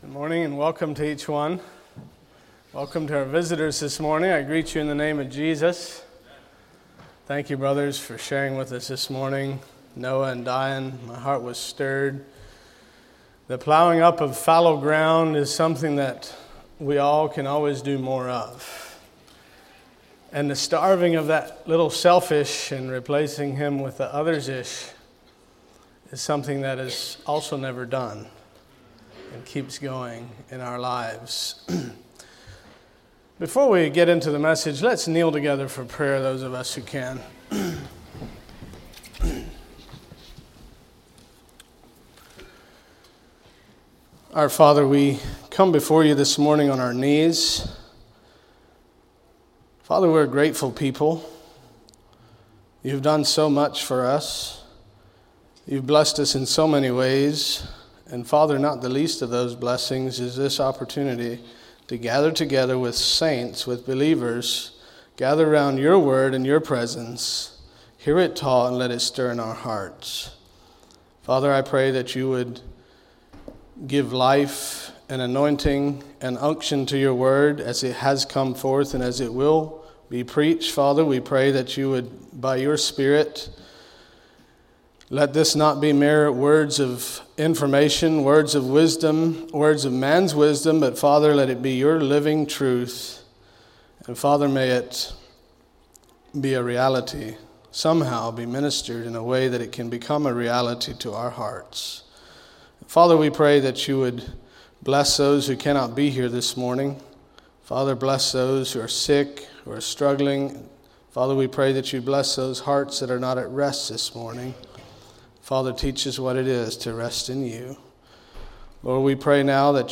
Sermons of 2021 - Blessed Hope Christian Fellowship